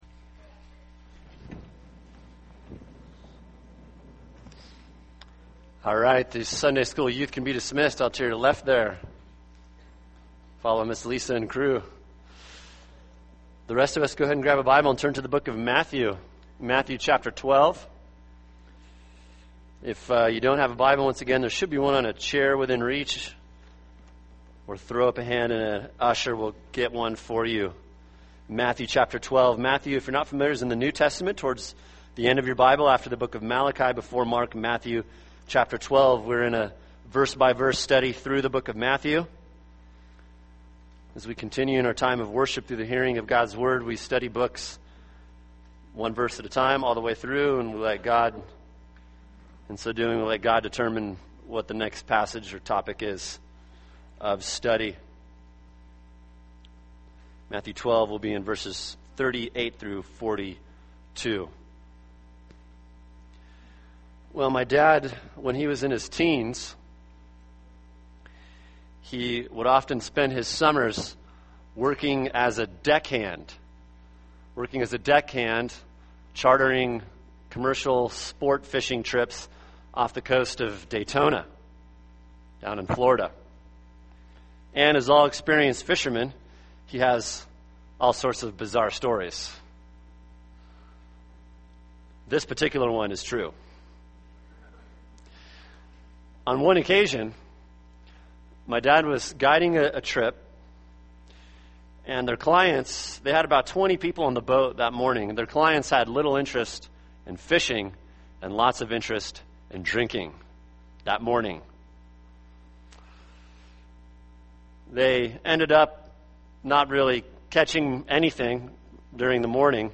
[sermon] Matthew 12:38-42 – When Christ is Spurned | Cornerstone Church - Jackson Hole